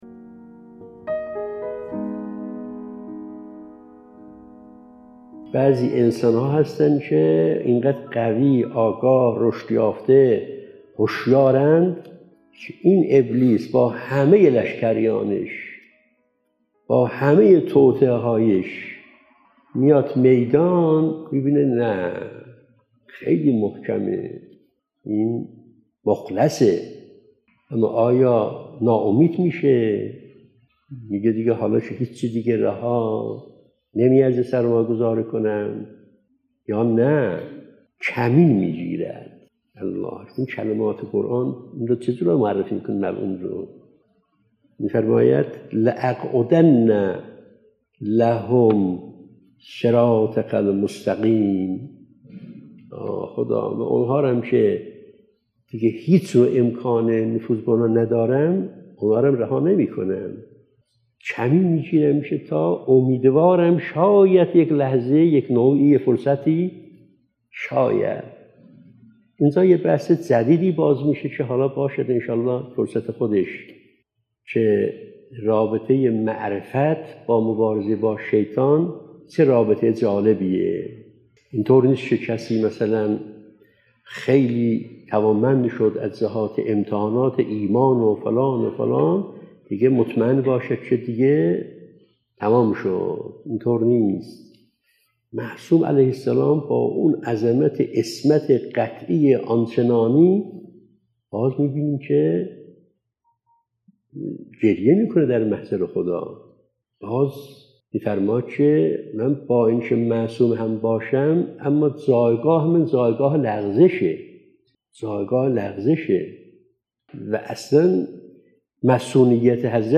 📍از جلسه اولین جمعه ماه قمری| ماه ذی‌الحجه 🎙ابلیس| ترفندهای شیطان برای انسان در قرآن کریم(۵) 📌شماره(۲۱) ⏳۸ دقیقه 🔗پیوند دریافت👇 🌐